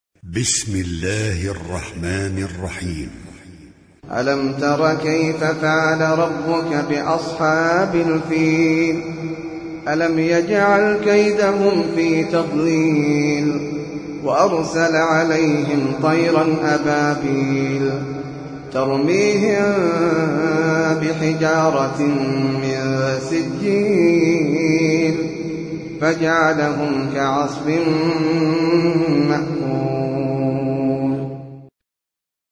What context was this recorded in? high quality